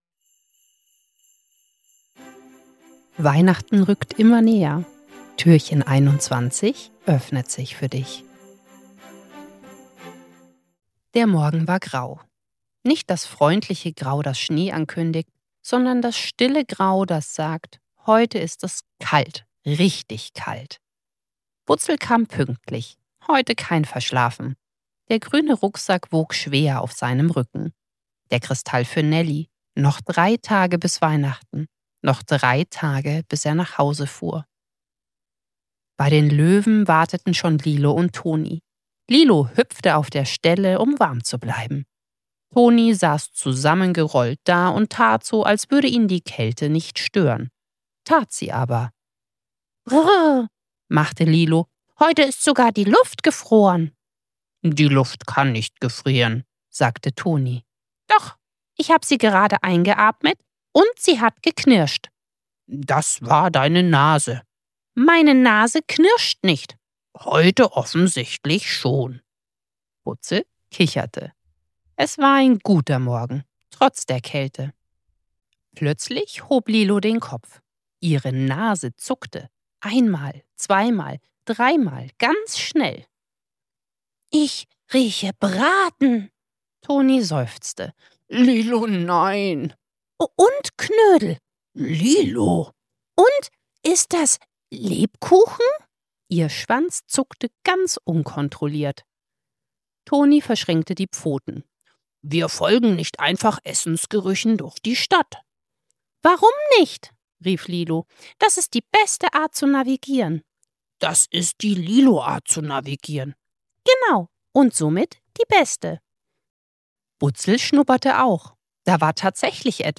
21. Türchen – Butzel und das Fest der offenen Türen ~ Butzels Adventskalender – 24 Hörgeschichten voller Herz & kleiner Wunder Podcast